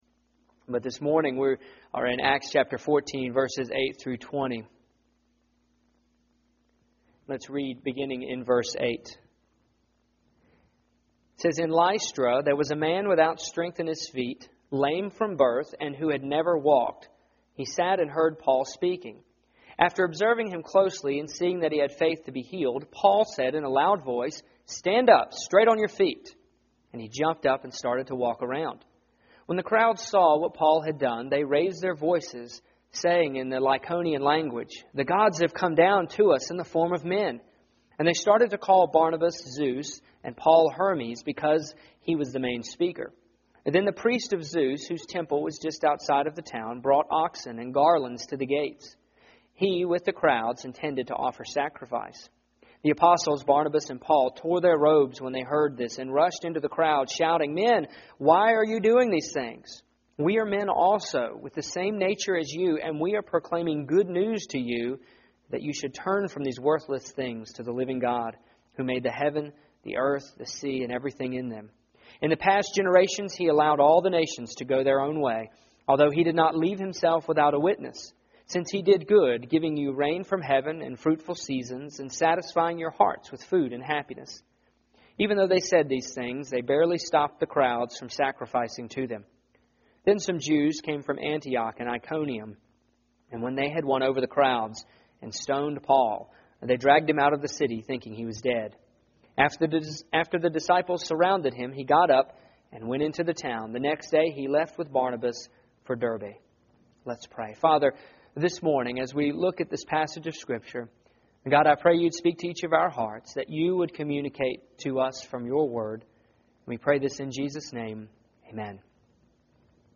Here is my sermon from Sunday September 14, 2008